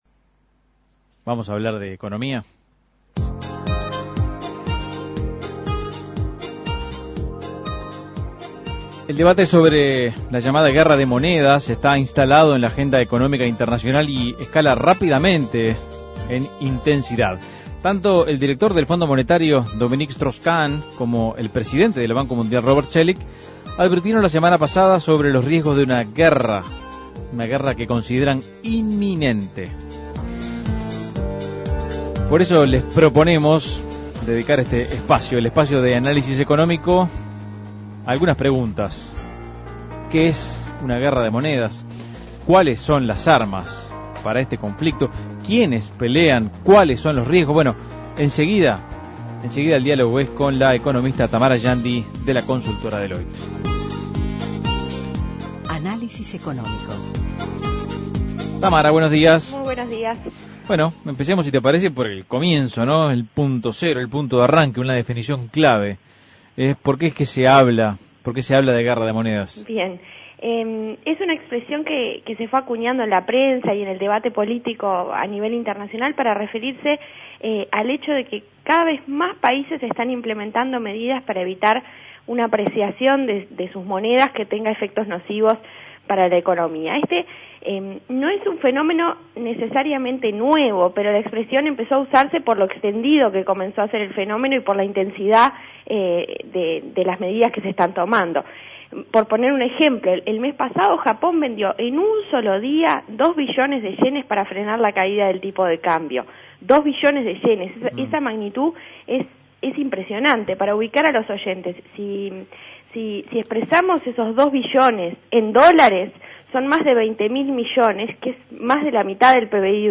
Análisis Económico ¿Qué es una "guerra de monedas"?